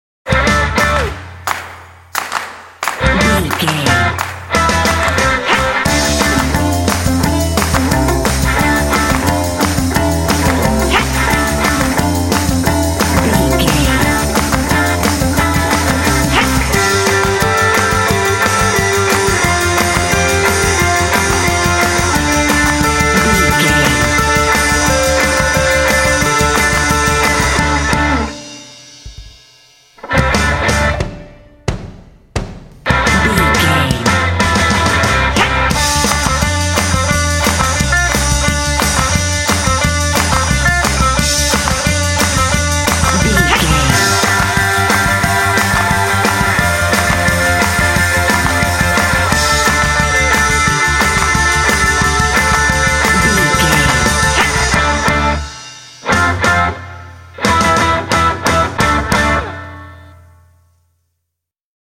Aeolian/Minor
powerful
energetic
heavy
drums
electric guitar
bass guitar
synthesiser
alternative rock
indie